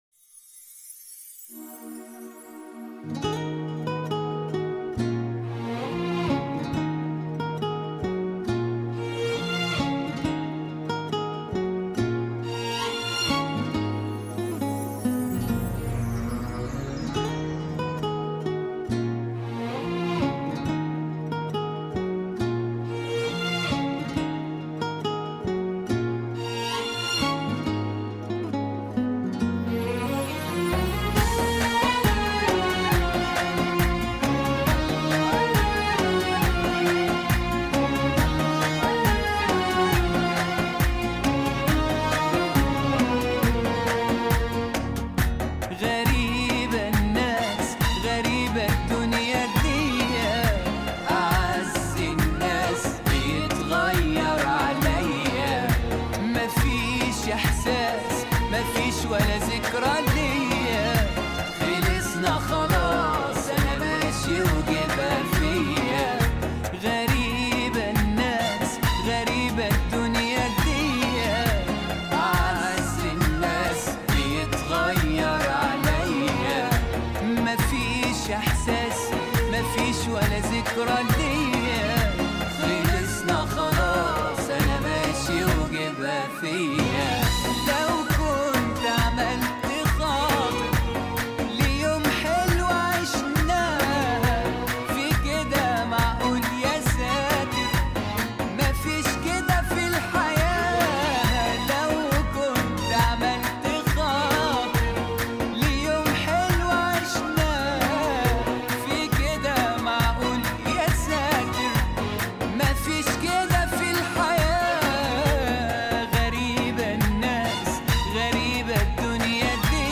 اغاني لبنانيه